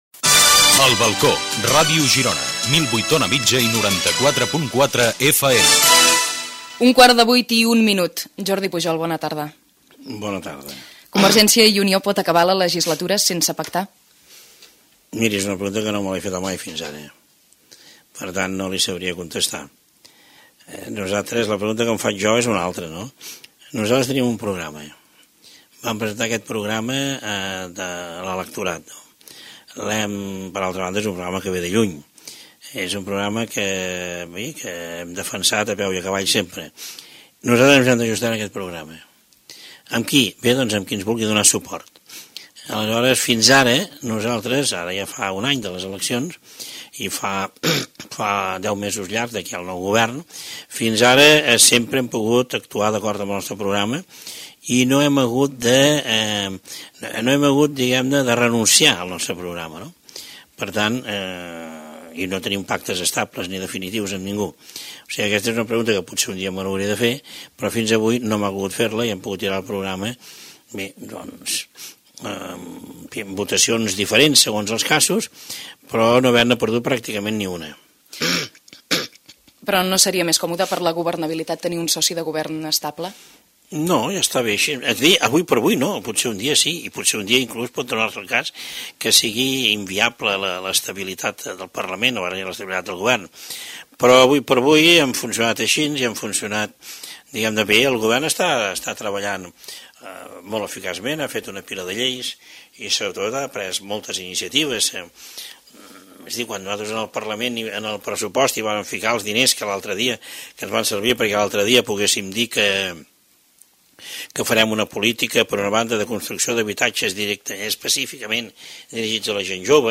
El Balcó: entrevista Jordi Pujol - Ràdio Girona, 2000